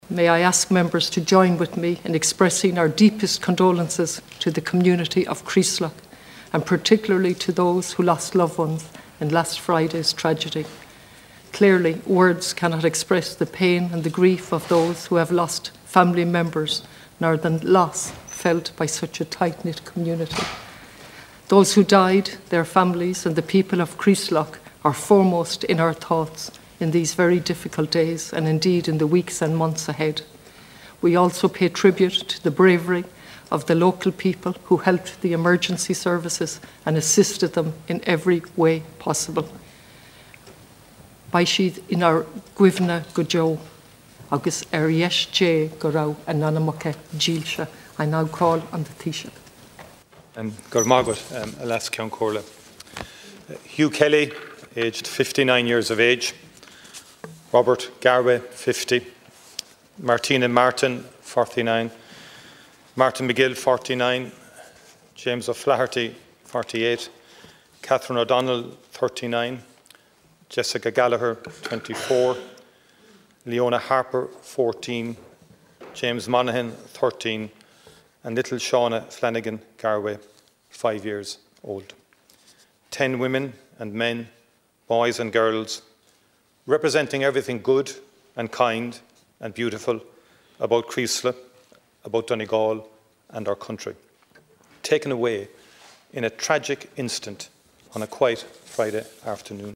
Tributes to the deceased, their families and the emergency services are being paid by all parties at the start of the week’s business.
The tributes and condolences were led by leas Ceann Comhairle Catherine Connolly and Taoiseach Michael Martin……..